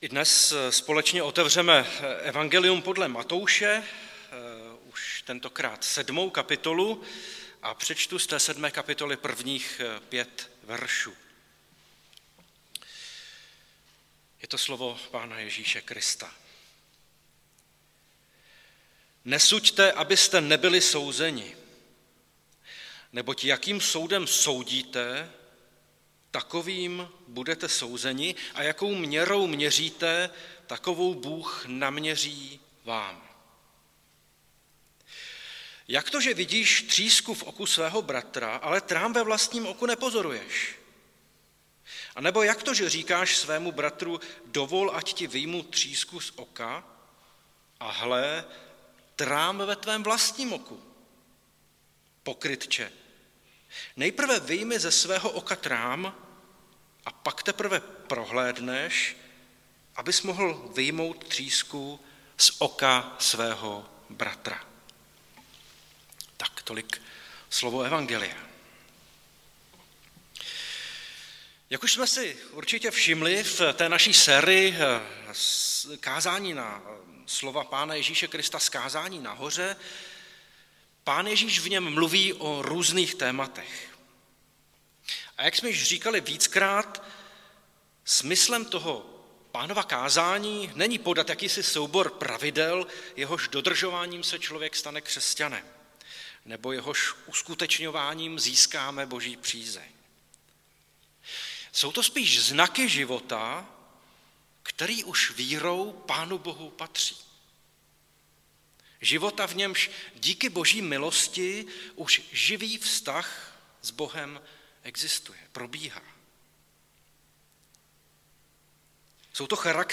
Nedělení kázání – 30.1.2022 O posuzování druhých